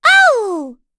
Dosarta-Vox_Attack3.wav